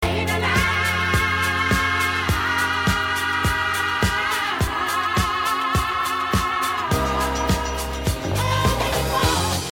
Un accord sur un break
descente vocale